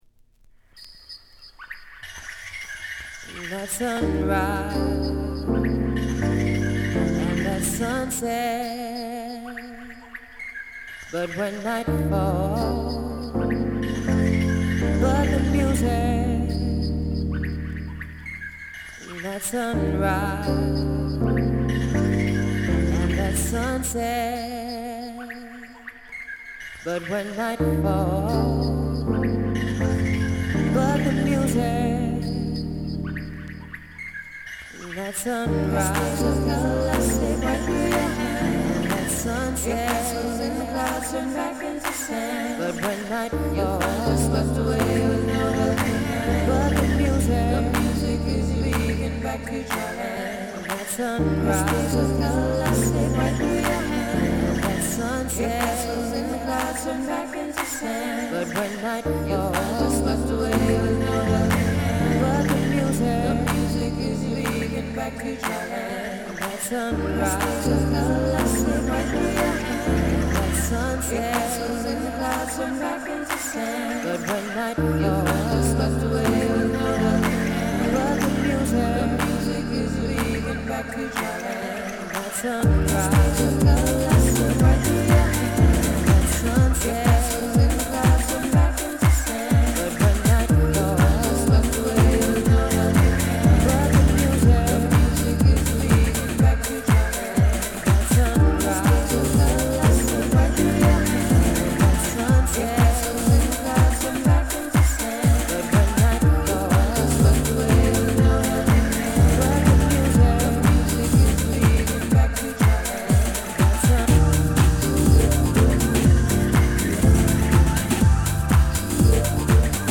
ソウルフルな女性ボーカルが素晴らしいです。